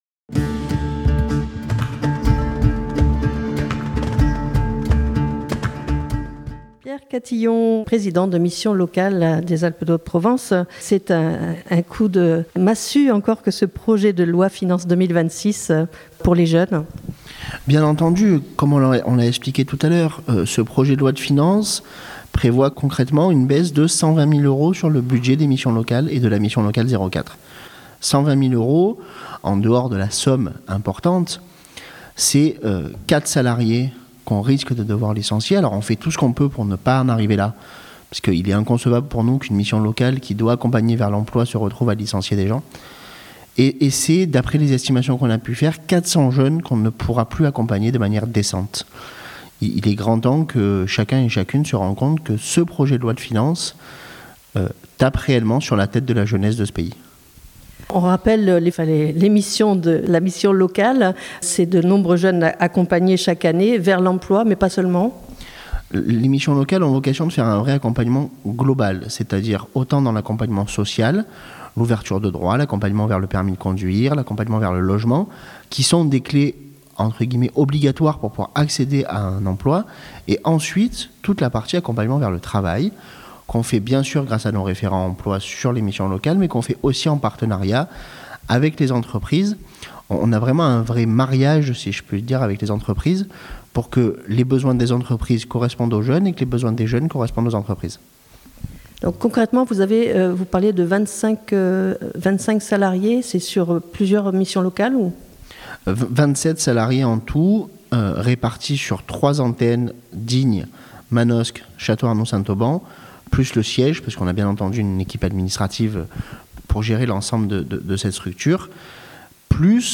Au micro de Fréquence Mistral Digne, Pierre Catillon rappelle que ces inquiétudes ne concernent pas uniquement l'insertion des jeunes, mais l'ensemble du secteur de l'Economie Sociale et Solidaire.